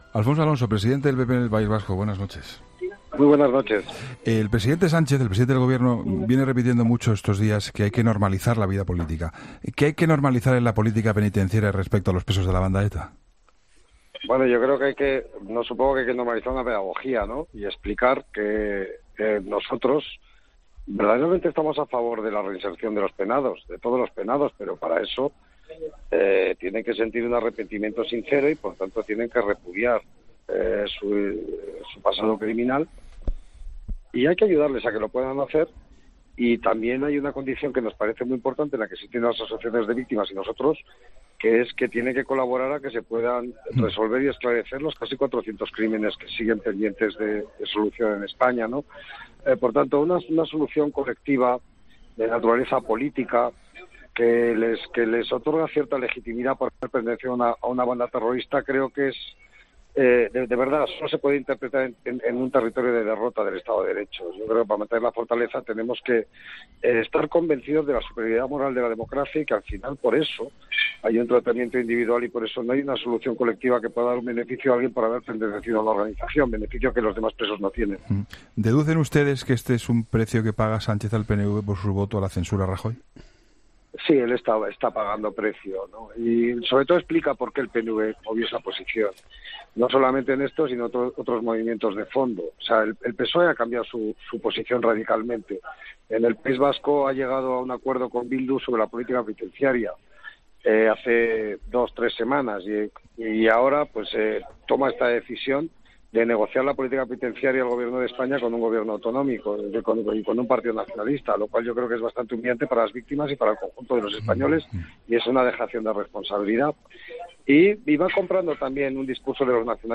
El presidente del PP en el País Vasco, Alfonso Alonso, ha analizado en 'La Linterna' la cifra de inscritos para votar en la elección del sucesor de...